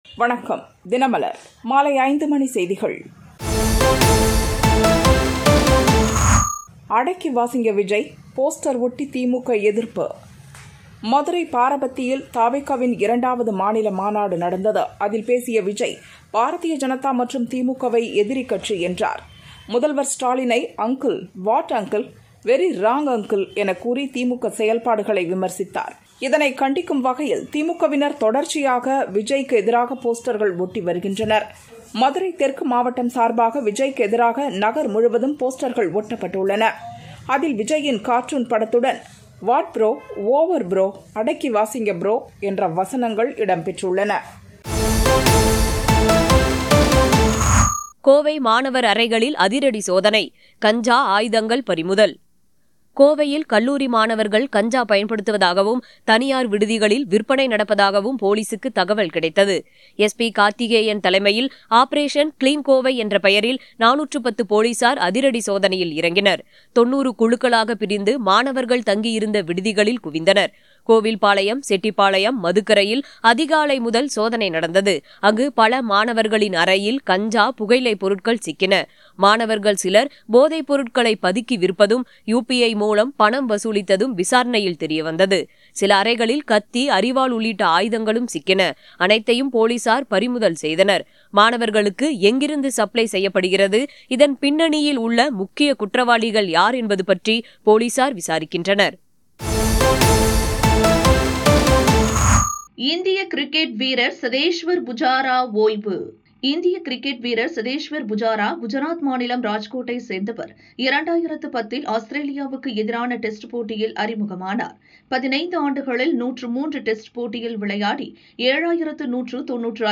தினமலர் மாலை 5 மணி செய்திகள் - 24 AUG 2025